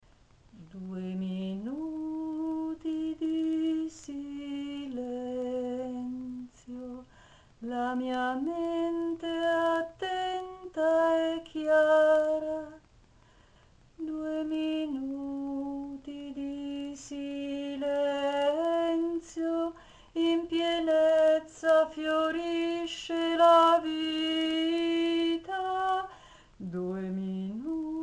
Canzoni in italiano
5 – “Canzoni distensive per il Rilassamento Profondo”: